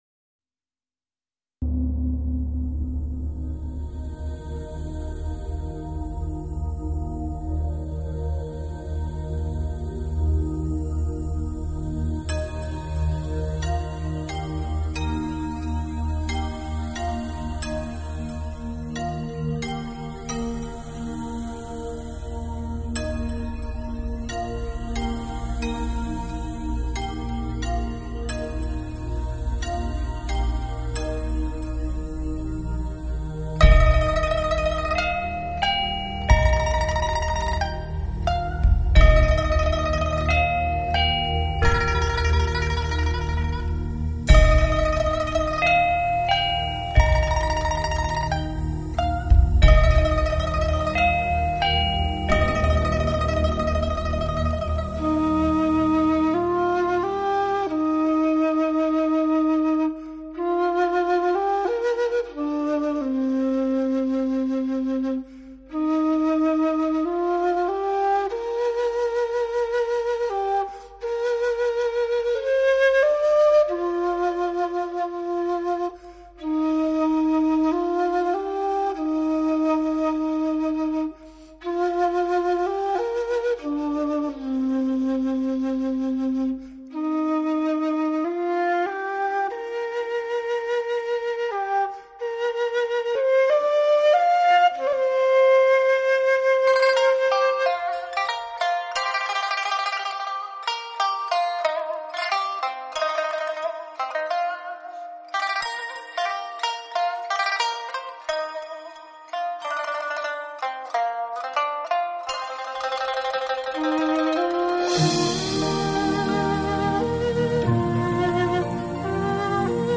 音乐类型: New Age
箫 笛 埙
古筝
琵琶
二胡
吉它
人声